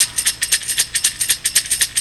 TAMBOU 2  -L.wav